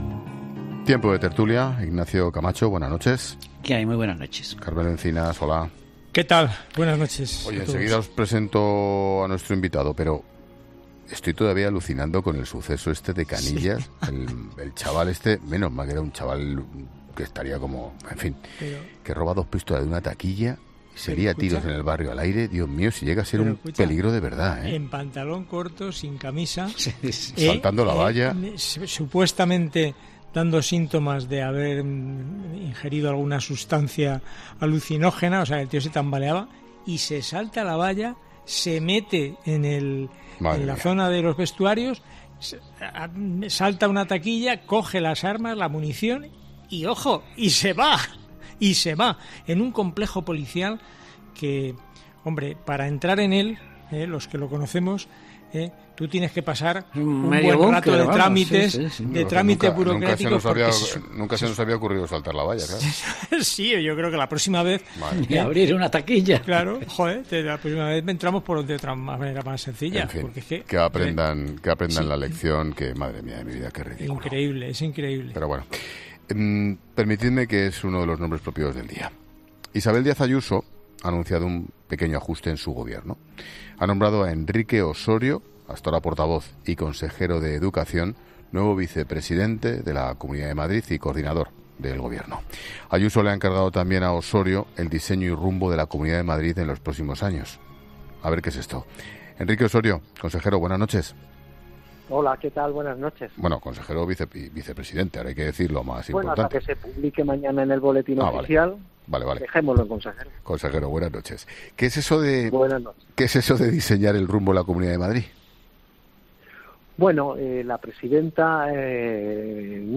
La presidenta de la Comunidad de Madrid, Isabel Díaz Ayuso, ha anunciado un cambio en estructura de su Gobierno, con el que otorga al consejero de Educación, Universidades y Ciencia y portavoz, Enrique Ossorio, también las competencias de Vicepresidencia. Ossorio ha pasado por los micrófonos de 'La Linterna' para explicar cómo diseñará el rumbo de Madrid con ese nuevo cargo asignado por Ayuso.